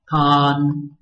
拼音查詢：【海陸腔】pon ~請點選不同聲調拼音聽聽看!(例字漢字部分屬參考性質)